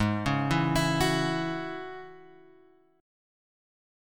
G# 7th Suspended 4th Sharp 5th